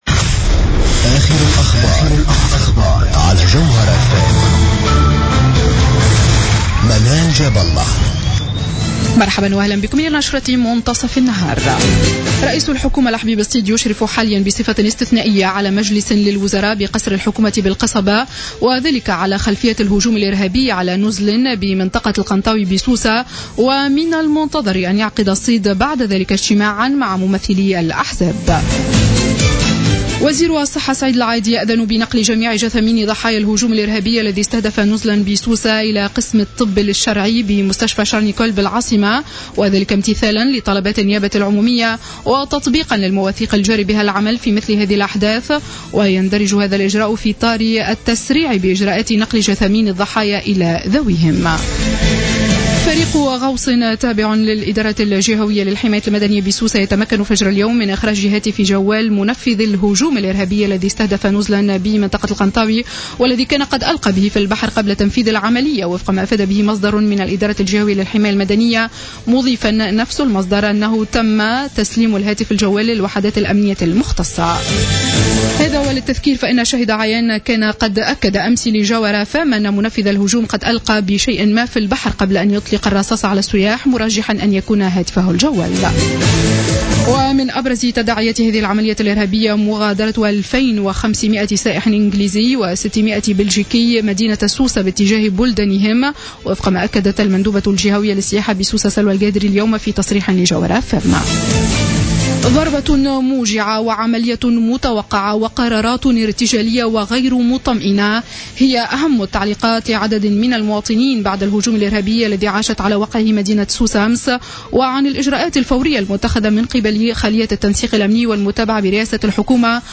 نشرة أخبار منتصف النهار ليوم السبت 27 جوان 2015